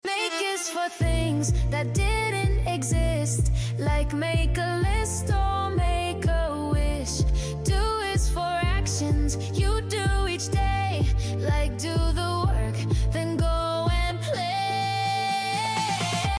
Want to learn how to use the Passive Voice in English? This fun, catchy grammar song will help you master it — effortlessly!